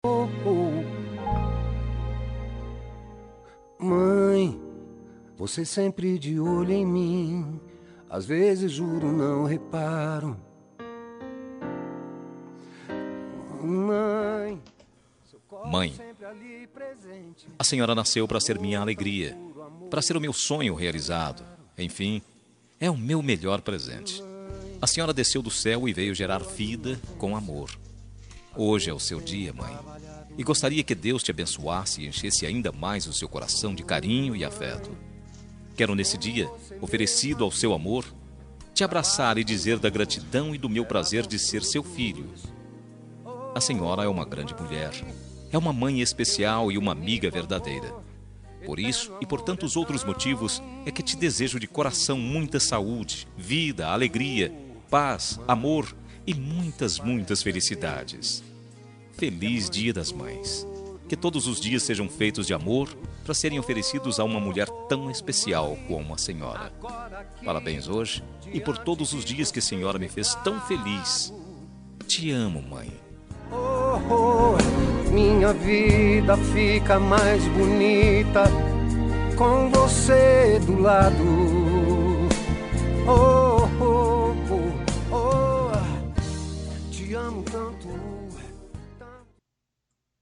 Dia das Mães – Para minha Mãe – Voz Masculina – Cód: 6520